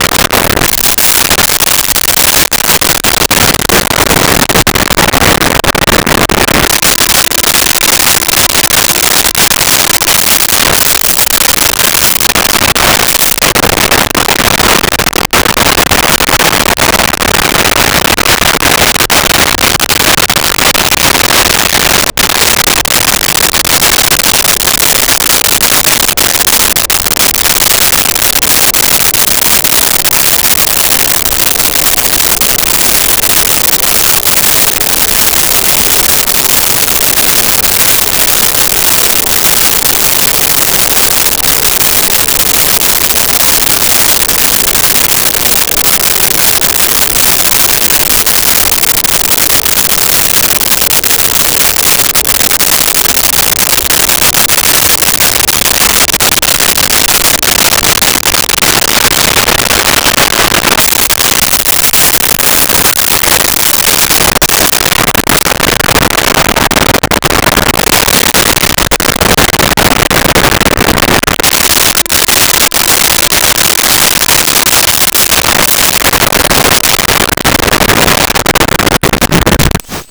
Subway Ride
Subway Ride.wav